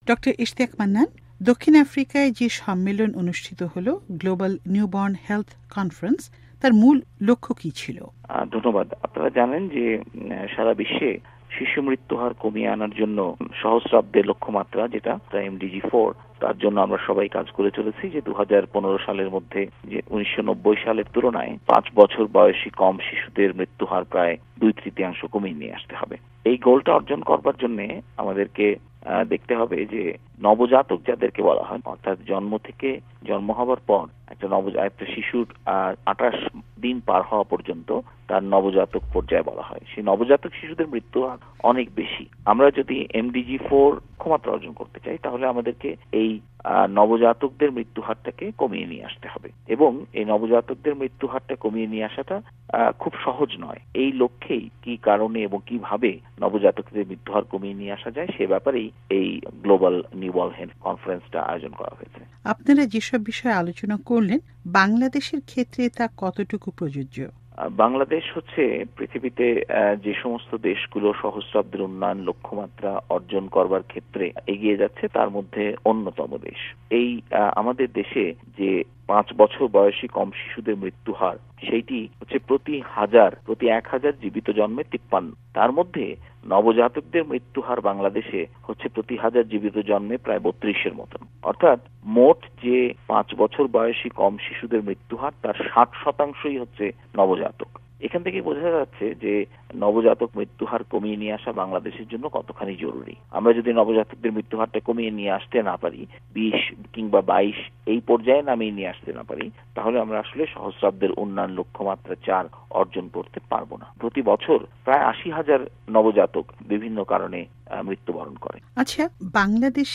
সাক্ষাত্কার